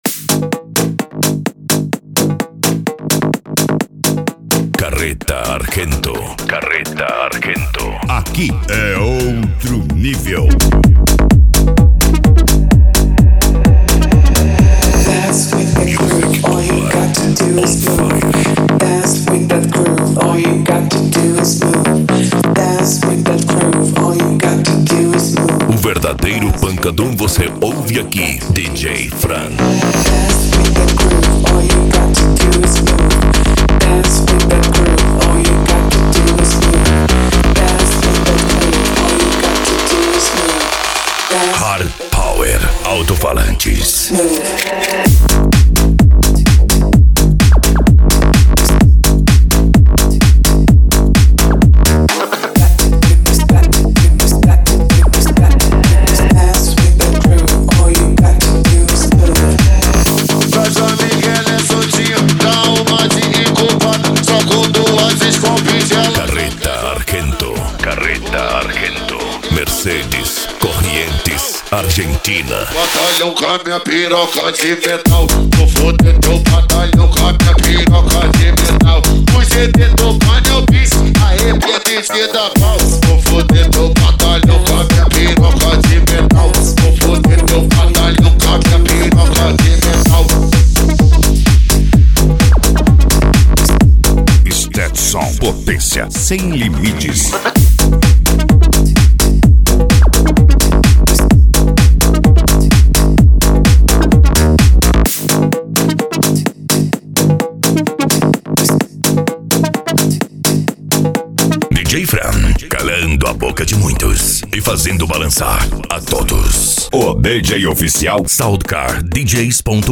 Bass
Euro Dance
Pagode
Remix